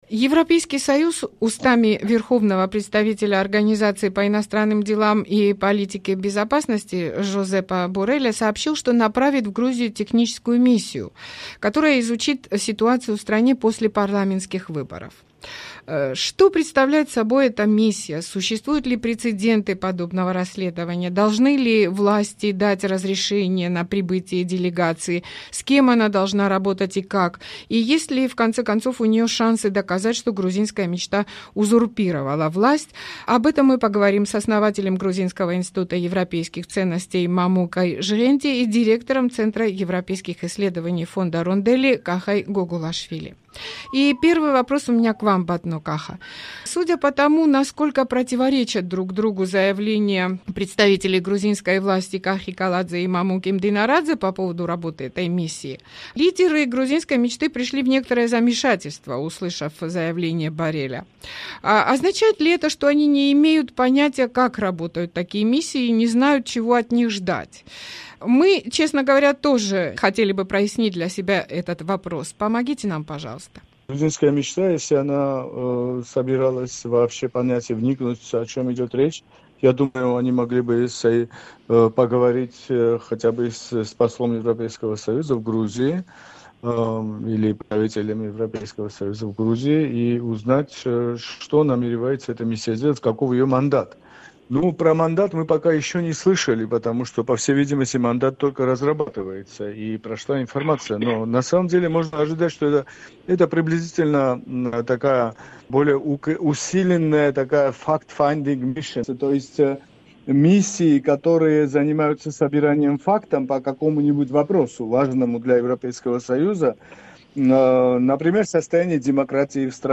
Гости за «Некруглым столом» считают, что протесты в Грузии против фальсификаций на выборах и международное расследование могут вынудить «Грузинскую мечту» назначить повторные выборы